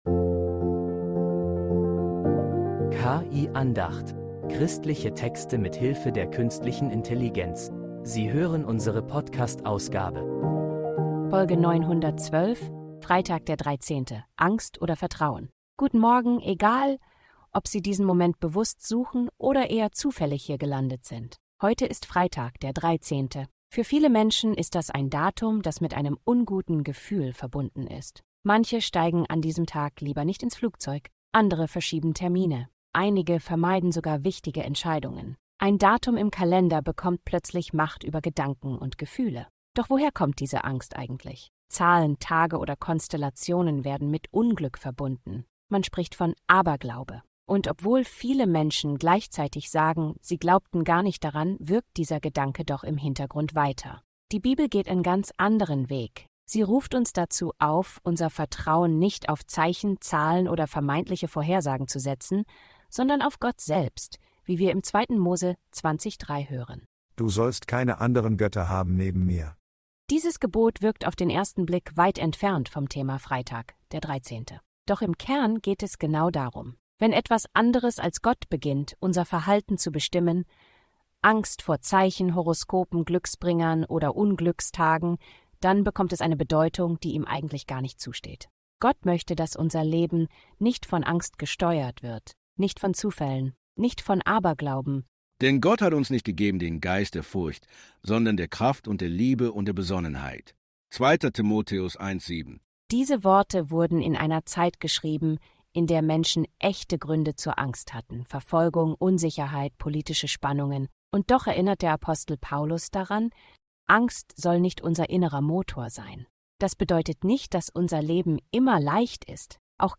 Diese Andacht lädt dazu ein, Angst loszulassen und neu auf Vertrauen zu setzen